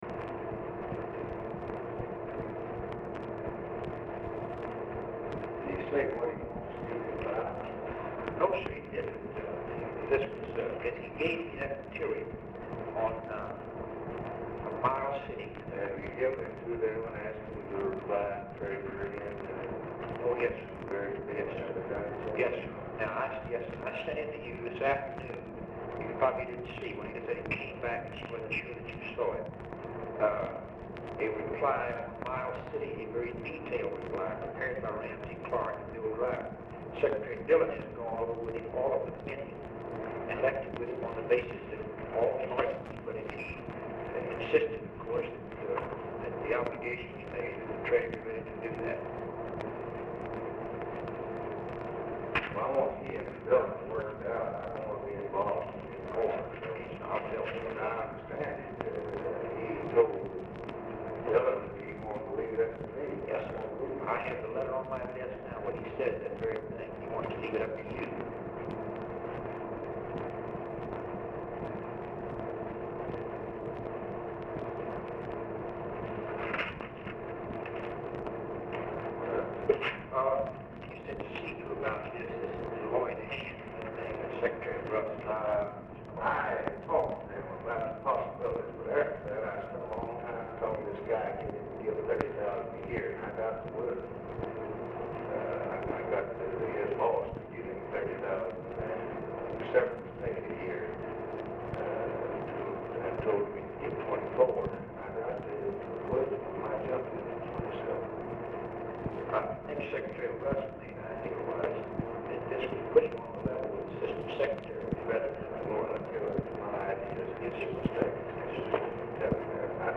POOR SOUND QUALITY; LBJ IS MEETING WITH JACK VALENTI, BILL MOYERS, RAMSEY CLARK
Format Dictation belt